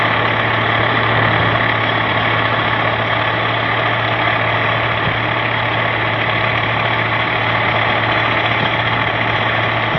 Еще один вариант звука трактора:
tractor3.wav